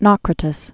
(nôkrə-tĭs)